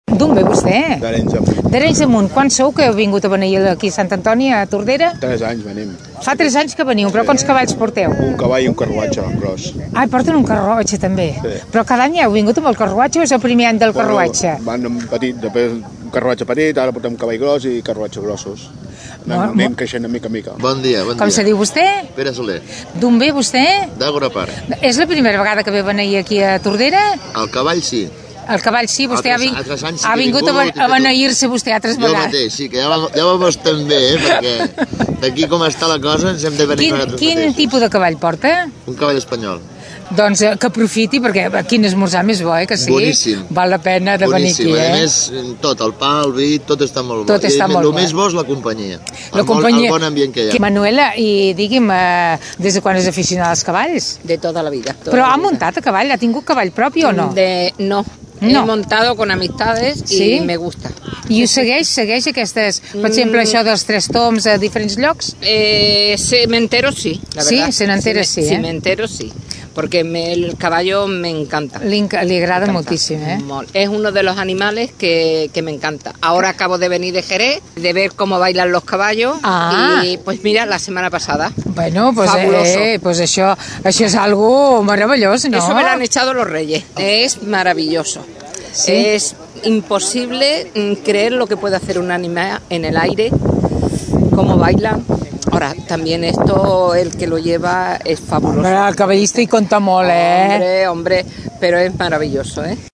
Escoltem algunes veus de participants durant la concentració al parc Prudenci Bertrana.
tres-tombs-participants.mp3